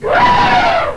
snd_24073_Elephant.wav